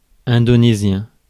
Ääntäminen
IPA : /indəˈniʒən/